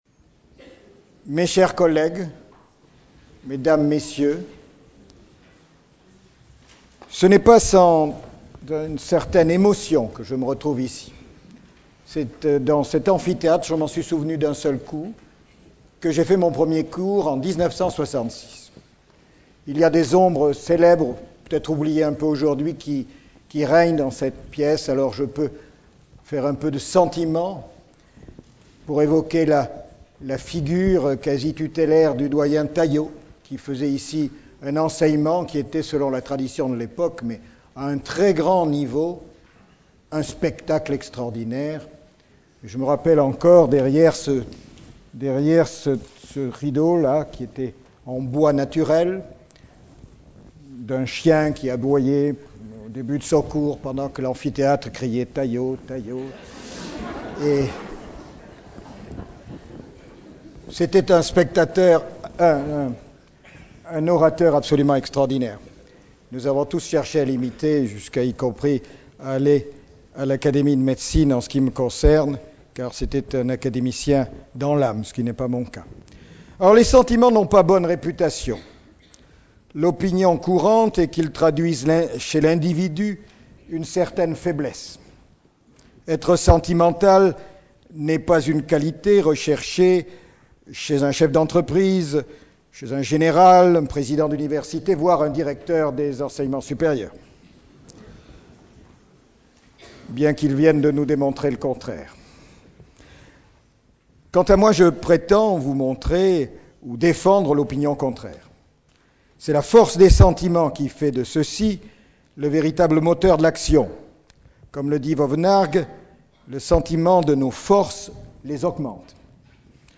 Conférence publique lors de la Cérémonie de Rentrée Solennelle des Universités de Bordeaux. 2004-2005